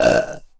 声乐语音语言 " 打嗝打嗝
描述：Burp Belch 使用以下设备记录和处理： 接口：focusrite scarlett 2i2Alesis firewire io14 麦克风：Rode NT1000SE 2200 AAKG C1000sSuperlux R102幻象供电带状麦克风Radioshack PZM压力区麦克风X2Optimus 333022边界麦克风X2Optimus 333017电容式麦克风现实驻极体电容立体声麦克风33919定制PZM 松下冷凝器，带定制48伏幻象单元X2Clock Audio C 009E RF边界MicrophoneSony立体声驻极体电容式麦克风ECM99 AOktaver IIMK55Oktava mke2AKG D95sBeyer Dynamic M58各种老式麦克风 便携式装置：Olympus VN8600PCZOOM H2 其他设备：Phonic MU802 MixerBehringer Behringer UCONTROL UCA222Korg Toneworks AX100G
标签： 声音 有趣的 机械的 愚蠢的 机器人 数据接口 机器人 打嗝 放屁 煤气 半机械人 嗳气 搞笑 小工具 智能 机器人 应用 机械 人工 disgustin克 空间 总量 星系 电子 仿生 外星人 人类 机器人
声道立体声